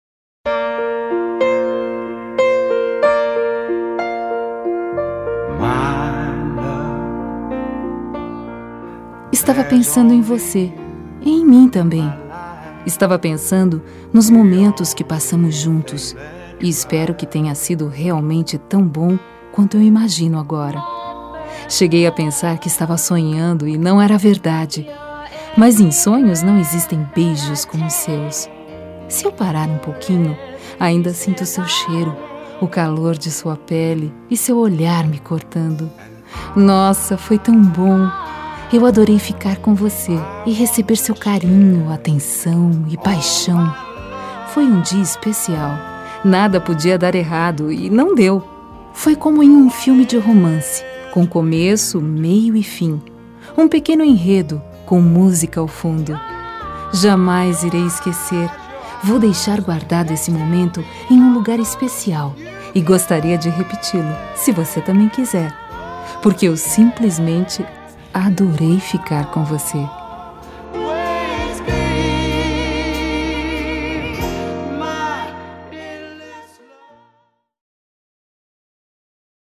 Telemensagens Românticas
Temas com Voz Feminina